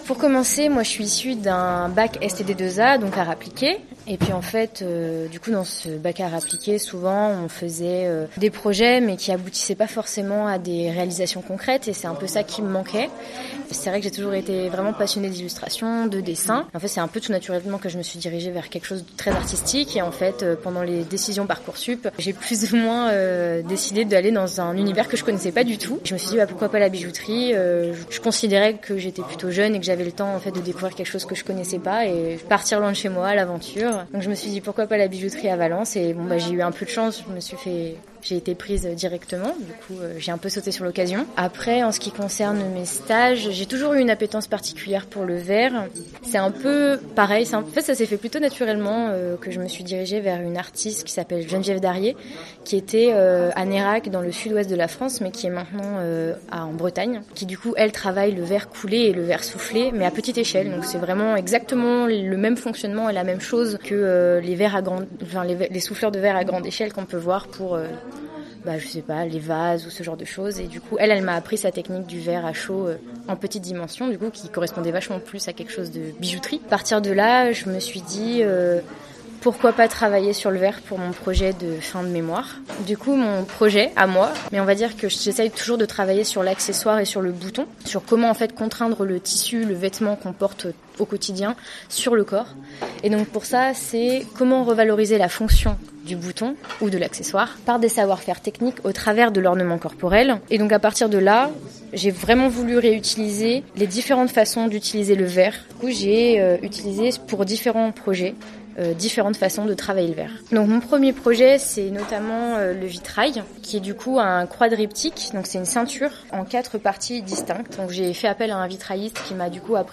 Afin de rendre compte de leur démarche, 7 étudiantes sur les 10 de cette 3e année sont interviewées dans le cadre de leur atelier et répondent chacune à 4 questions :
Un extrait de l’interview…